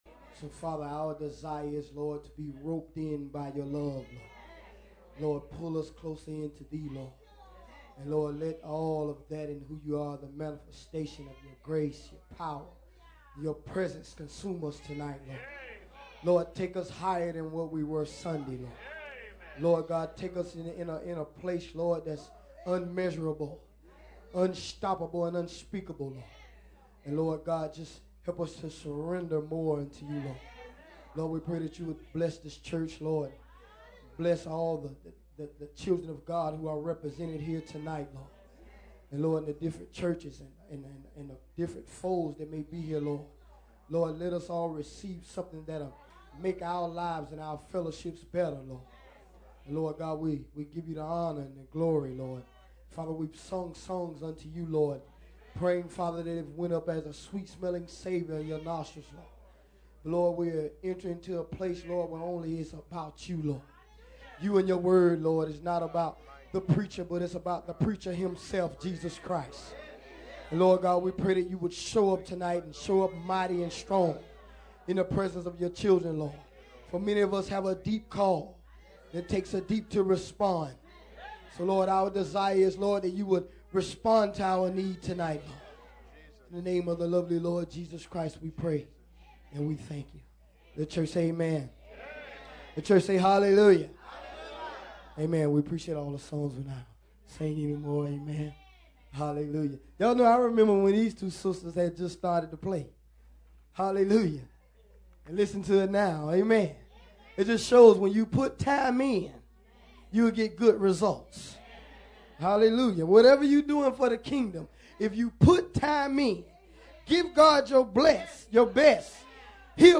Please REGISTER or LOG-IN to LiveStream or View Archived Sermons.